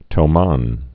(tō-män)